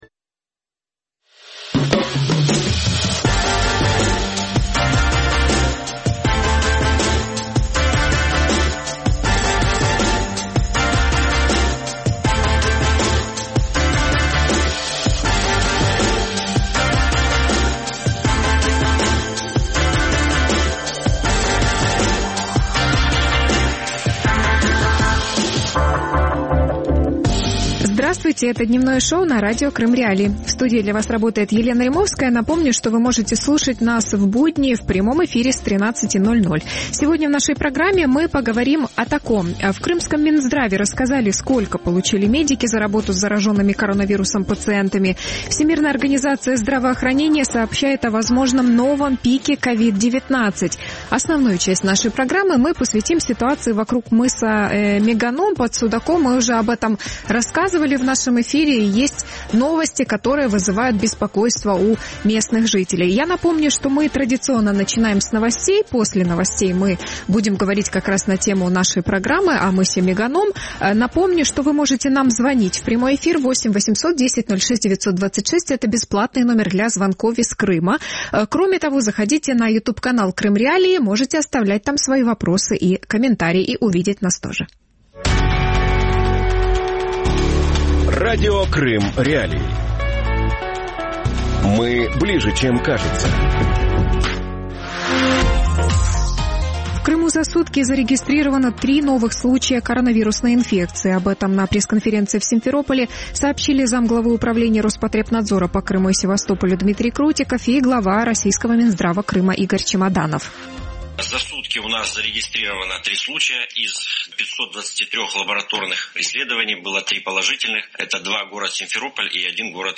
Мыс Меганом и форум «Таврида» | Дневное ток-шоу